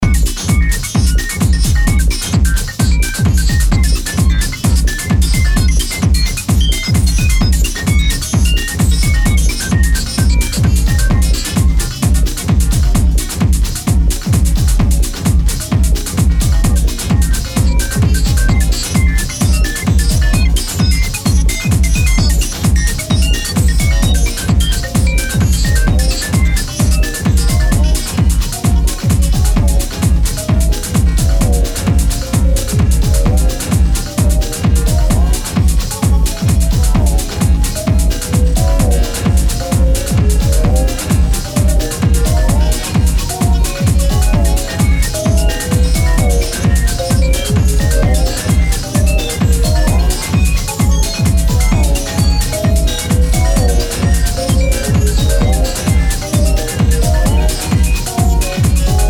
歪ませたハードキック、電子音の乱反射空間にデトロイト的なムーディーコードが姿を現す